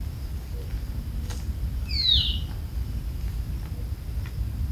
Bailarín Naranja (Pipra fasciicauda)
Nombre en inglés: Band-tailed Manakin
Localidad o área protegida: Parque Provincial Teyú Cuaré
Condición: Silvestre
Certeza: Fotografiada, Vocalización Grabada